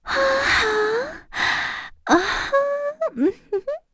Erze-Vox_Hum_kr.wav